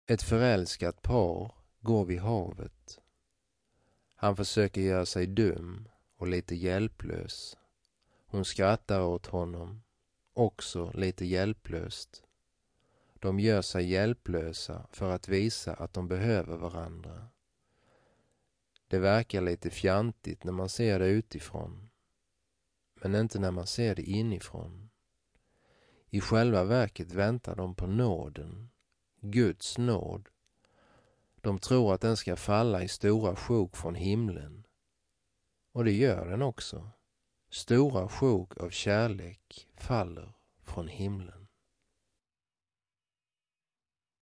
Vals
gitarr